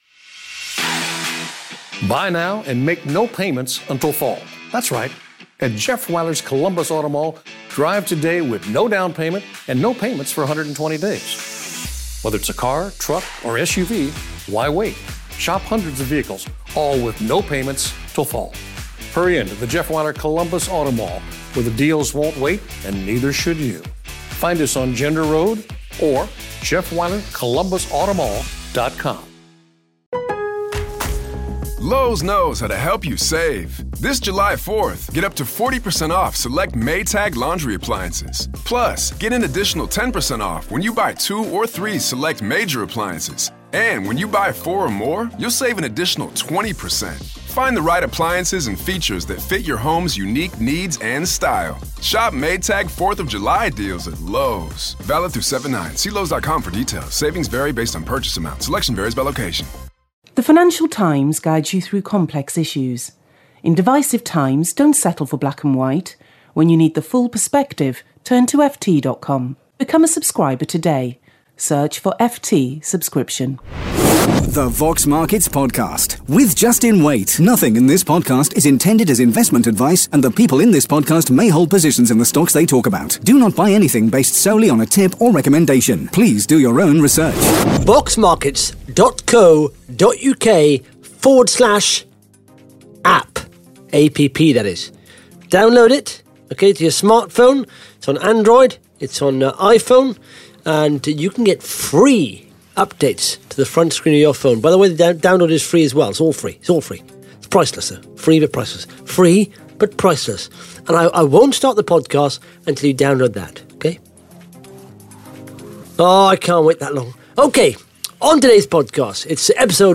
(Interview starts at 2 minute 12 seconds)